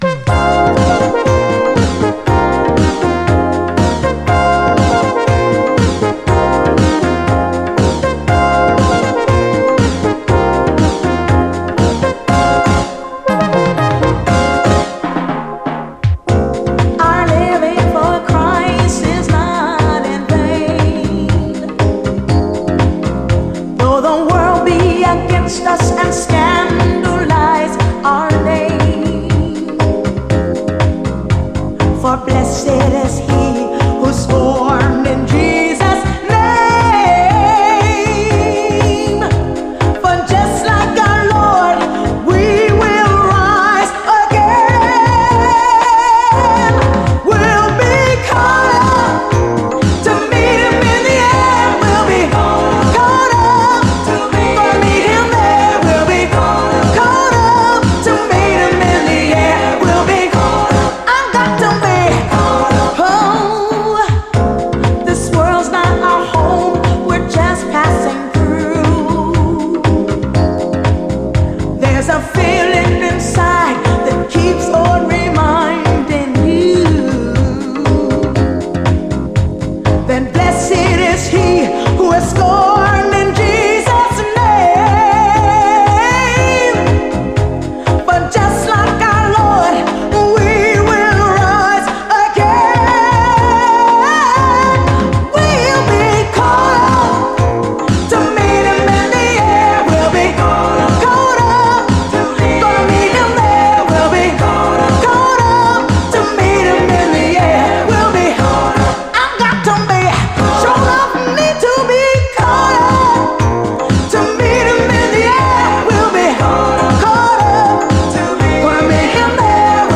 SOUL, 70's～ SOUL
エモーショナルな高揚感のレイト80’S LA産シンセ・ソウル〜ゴスペル・ソウル12インチ！
LA産インディー・モダン・ソウル〜ゴスペル・ソウル12インチ！
両面インストも収録。